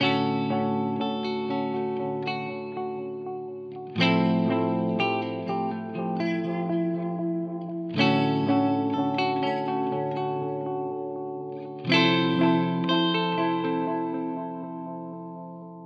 Fender style Amp, Overdrive und Delay
fenderduosonicfenderstyleampmitdelayundoverdrive.wav